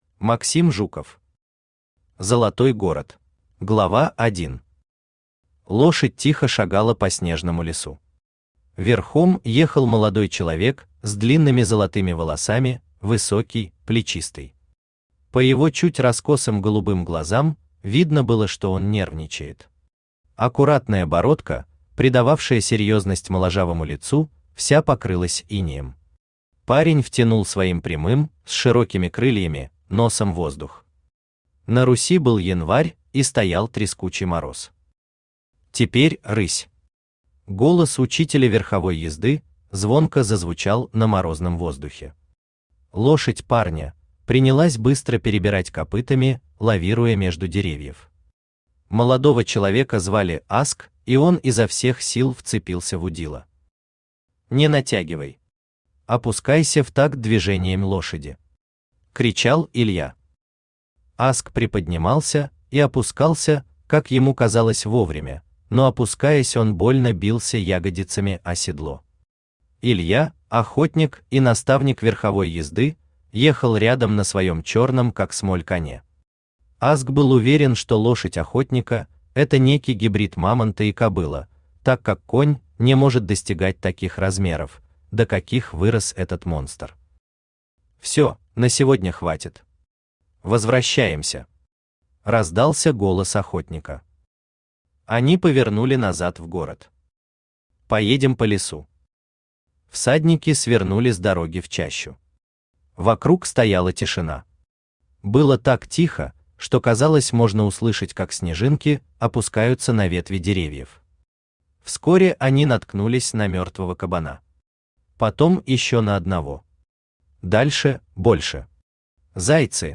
Aудиокнига Золотой город Автор Максим Александрович Жуков Читает аудиокнигу Авточтец ЛитРес.